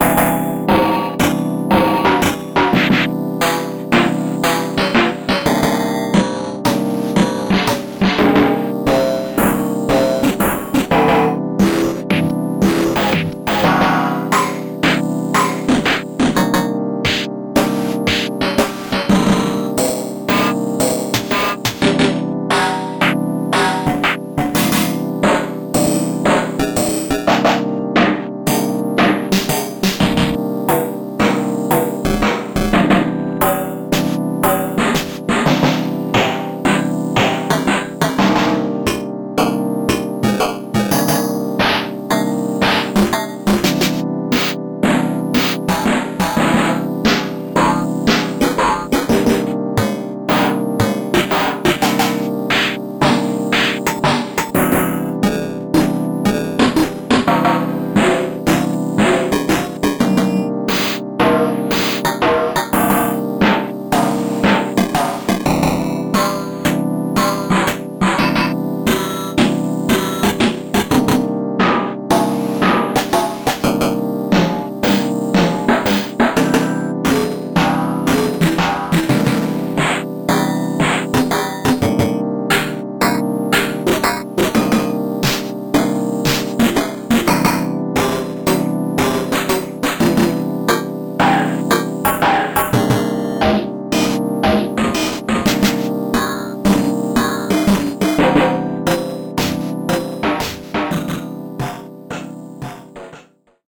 超マニアックなロービットサンプラー極太単発ドラムサンプリングCD
※サンプリングCD試聴用デモはMP3ファイルのため、実際の商品の音よりも劣化して聞こえますことをご理解ください。
レコード盤のスクラッチノイズやアナログテープノイズ、各種ロービットサンプラーを使った意図的なビット落とし、様々なタイプのエイリアスノイズや量子化ノイズ。それらの音楽的、芸術的ノイズにまみれた単発ドラムサンプルを大量に収録
ドラムと一緒に鳴っているシンセサイザーやキーボードの音は、商品には入っていません。
bitware-snare-demo.mp3